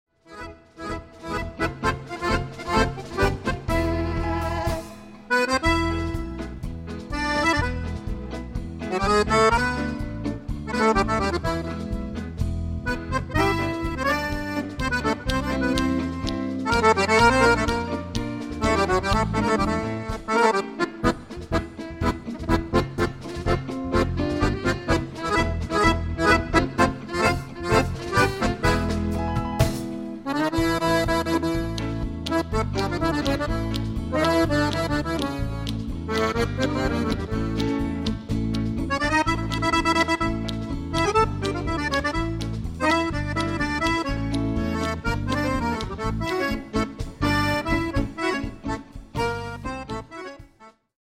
paso